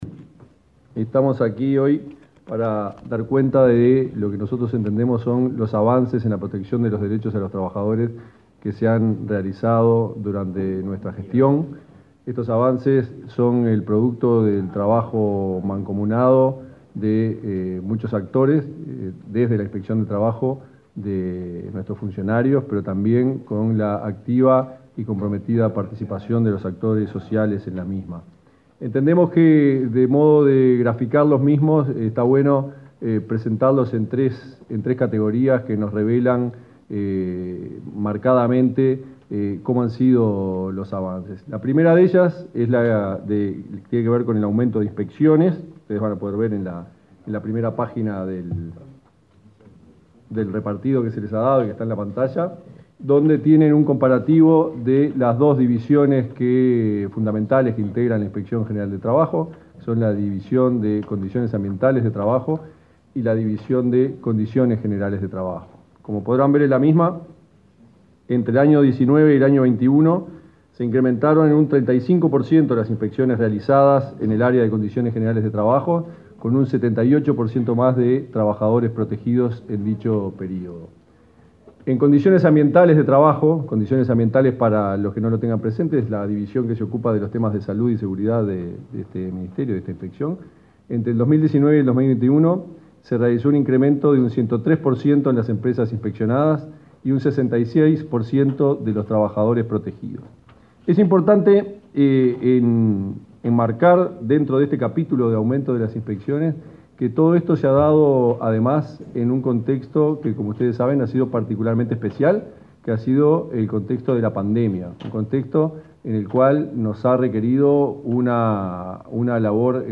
Palabras de autoridades del Ministerio de Trabajo y Seguridad Social 22/11/2022 Compartir Facebook X Copiar enlace WhatsApp LinkedIn Este martes 22, el ministro de Trabajo, Pablo Mieres, y el inspector general del Trabajo, Tomás Teijeiro, informaron a la prensa sobre avances registrados en la protección de los derechos de los trabajadores.